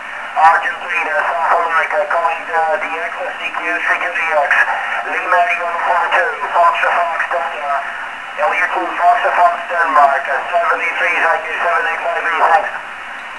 SSB